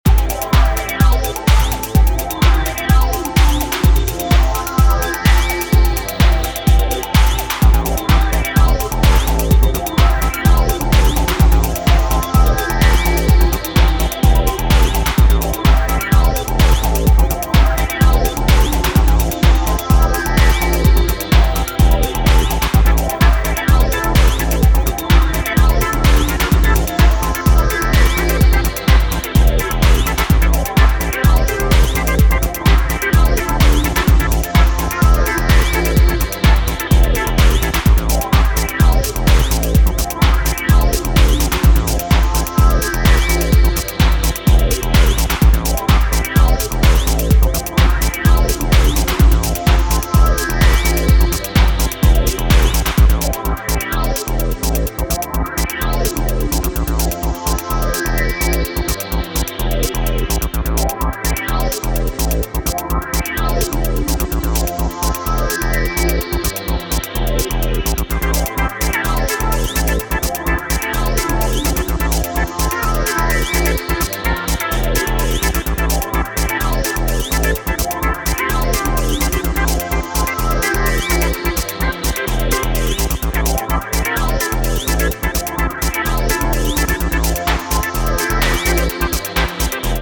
93年デトロイト・テクノ最高傑作のひとつがリマスター再発。